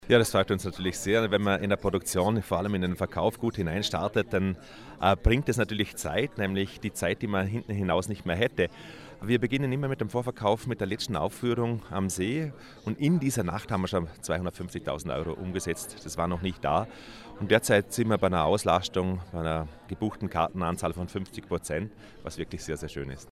Pressekonferenz Festspielprogramm 2020 news